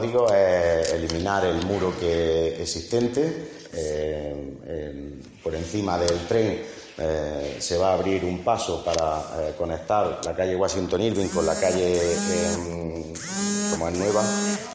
Miguel Angel Fernández Madrid, concejal de urbanismo